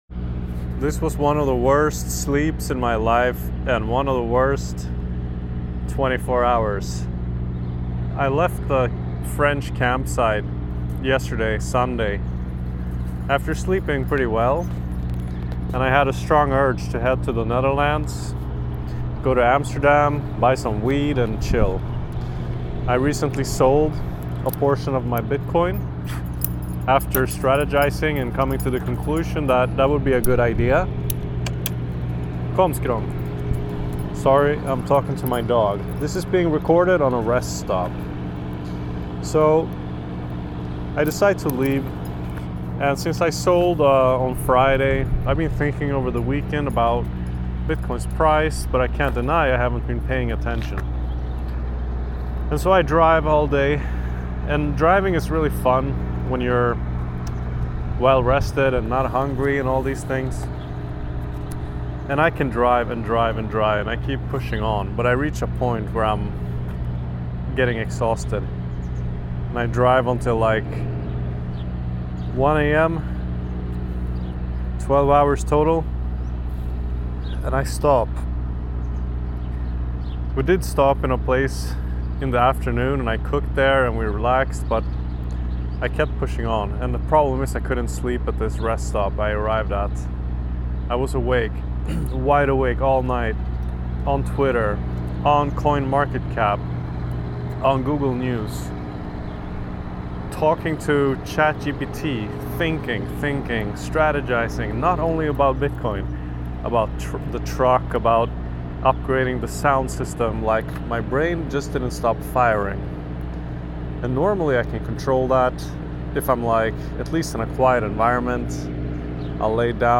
It was recorded on a rest stop between France and Belgium, somewhere in France along the toll roads.